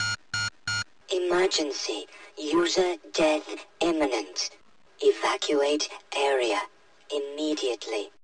[Three beeps] Emergency: User death imminent.